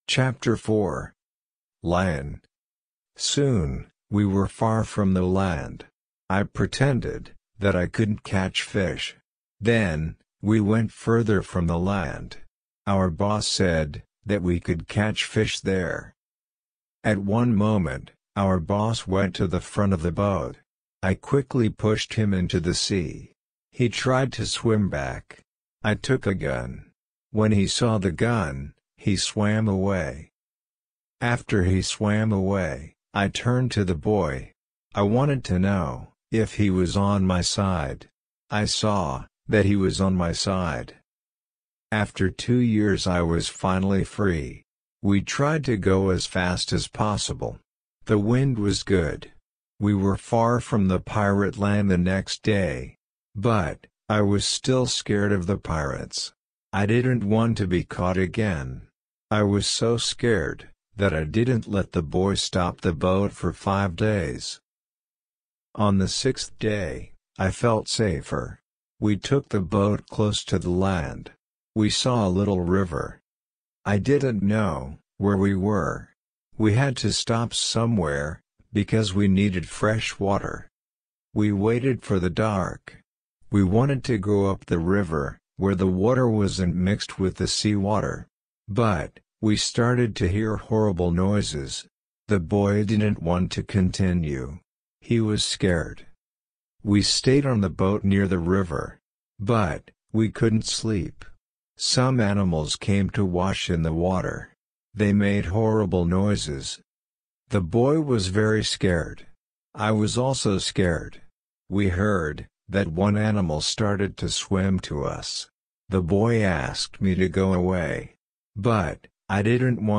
RC-L2-Ch4-slow.mp3